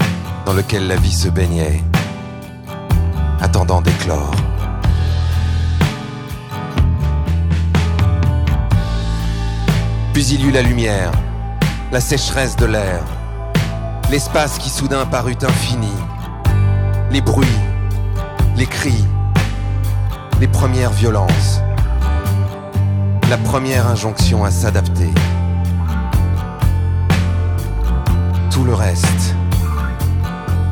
poèmes et quelques textes en prose
une expérience poétique portée par la musique
guitariste, compositeur et interprète
Chanson francophone - Chansons à texte